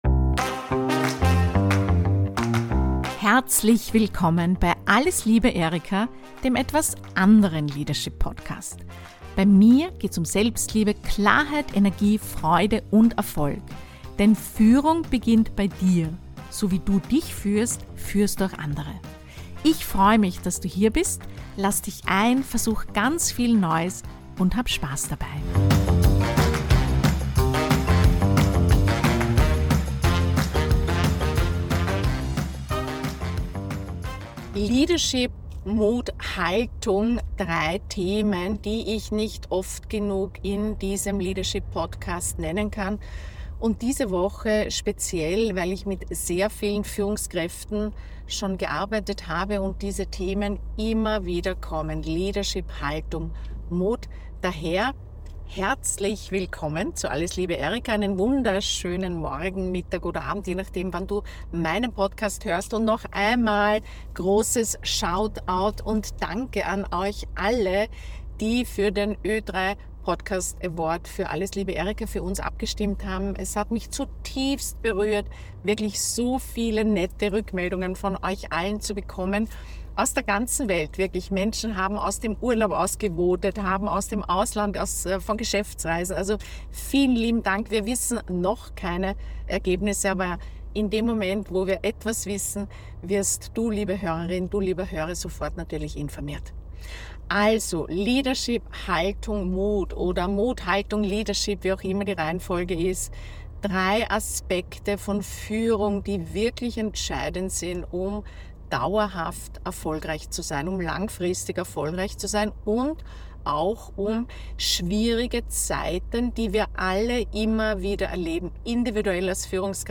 Diese spontane Episode aus dem Auto ist eine kraftvolle Erinnerung an das, was echte Führungspersönlichkeiten trägt, besonders dann, wenn es unbequem wird: Mut. Haltung. Leadership.